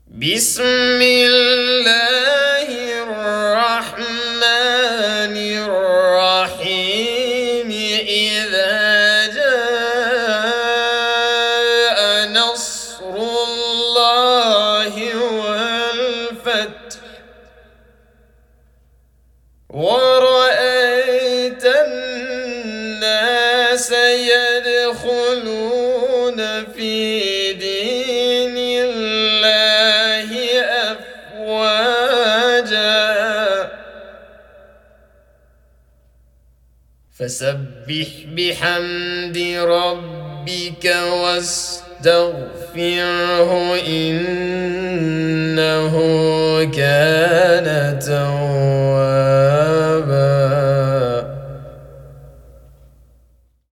알 나스르 (무자와드)